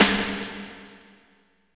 49.10 SNR.wav